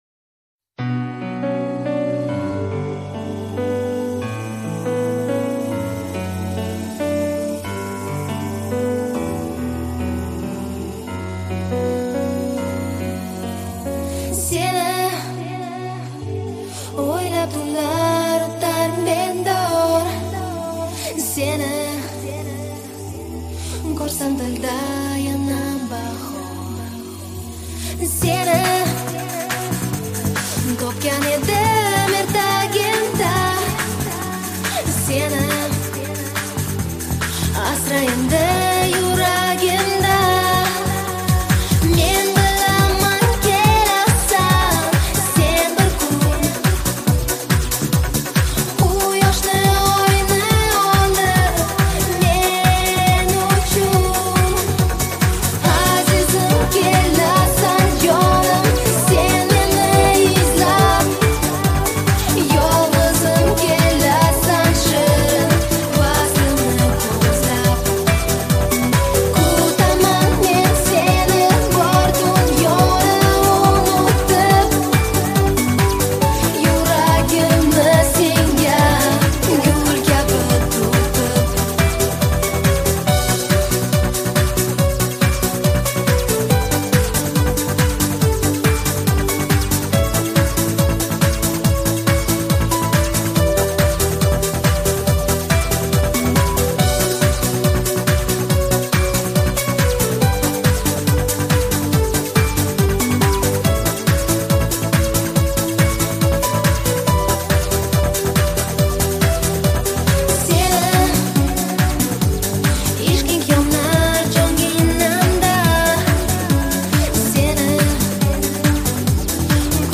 • Жанр: Индийские песни